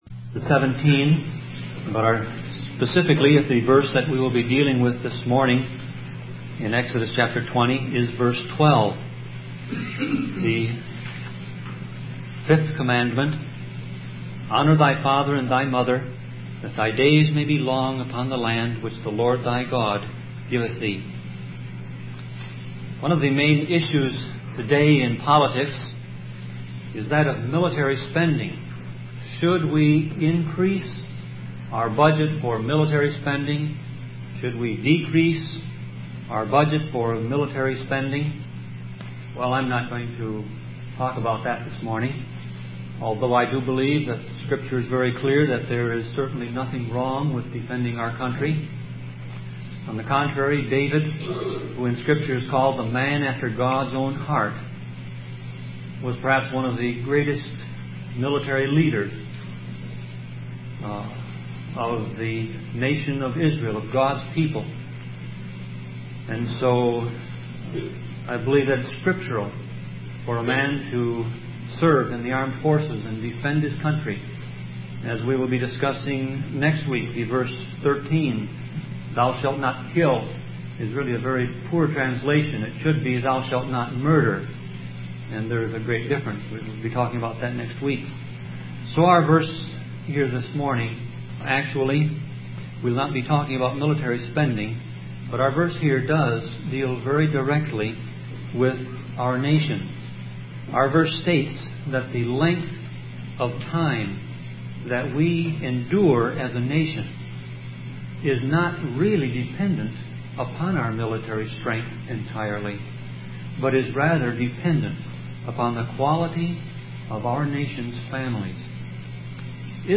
Series: Sermon Audio Passage: Exodus 20:12 Service Type